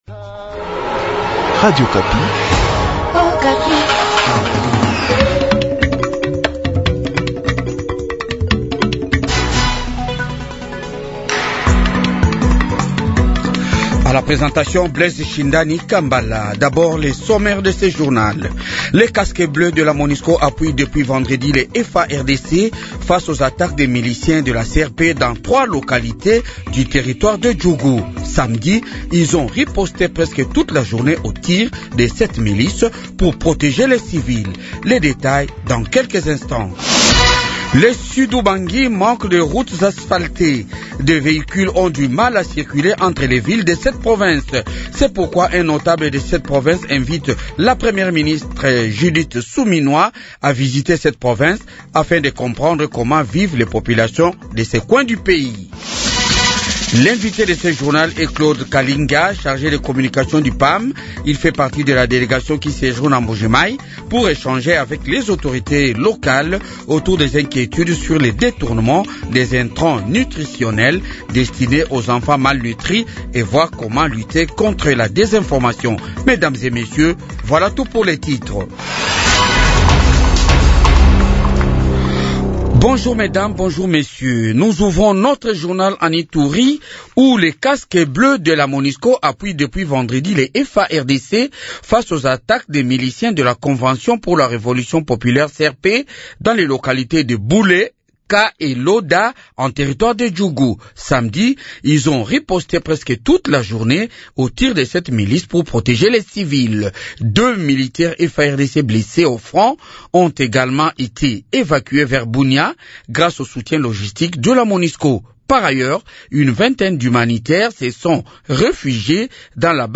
Jounal de 8h